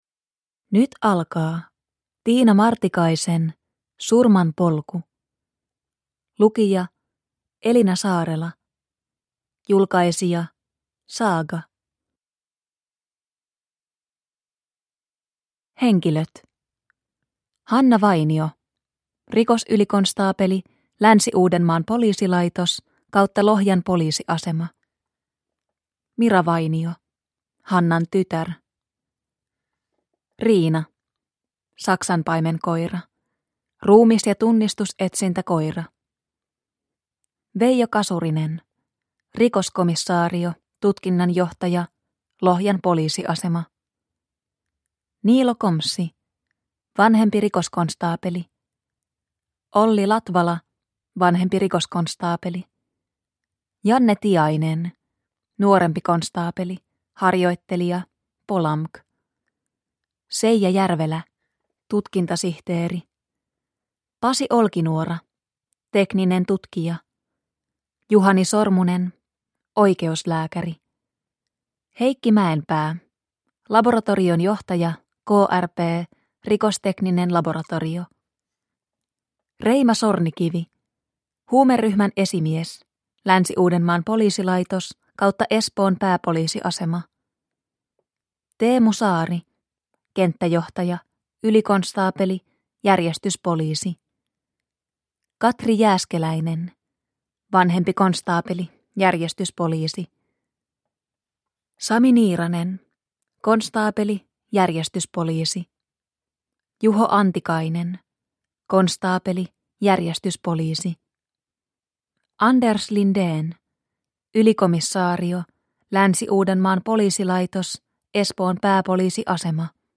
Surmanpolku / Ljudbok